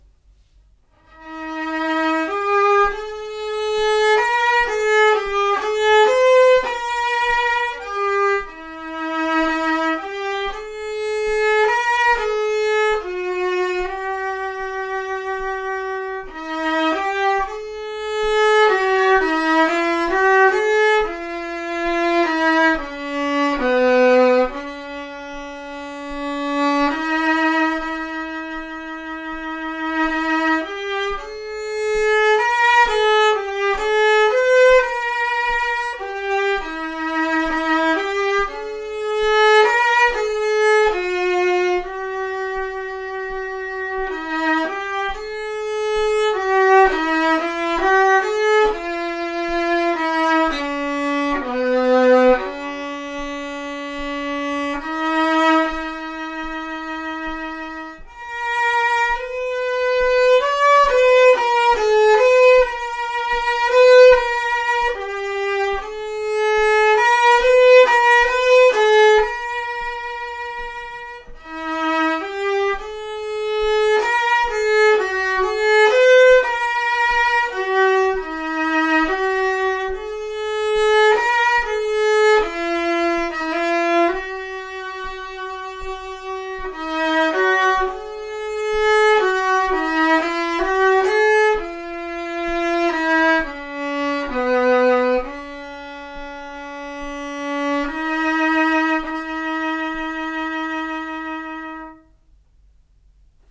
Instrumentets ton är fortfarande mörk men inte längre enligt min uppfattning obehagligt mörk.
Mikrofonen är på ca. två meters avstånd från fiolen vilket gör att stråkljud/brus hörs tydligt. Inspelningen har gjorts med en Zoom R8 inspeningsapparat. Den interna mikrofonen har använts.
Följande exempel visar det justerade fiolen. Man hör tydligt en förändrad diskant jämfört med det första exemplet.